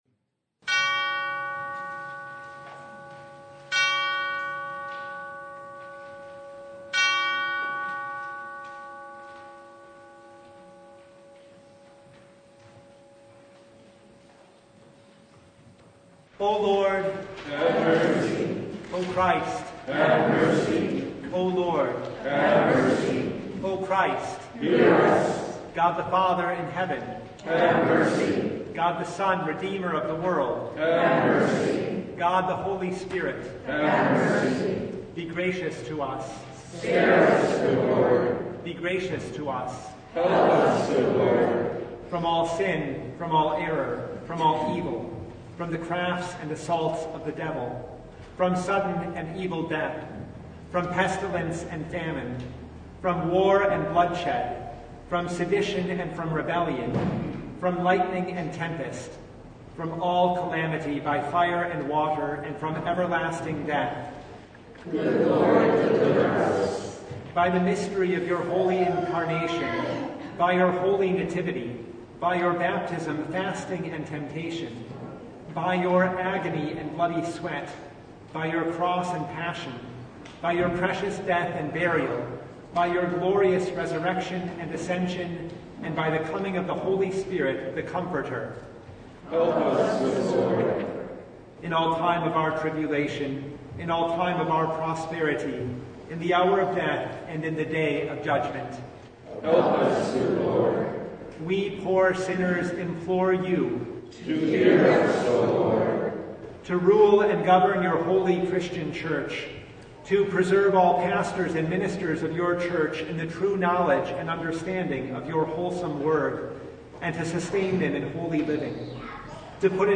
Passage: Exodus 9:1-35 Service Type: Lent Midweek Noon
Topics: Full Service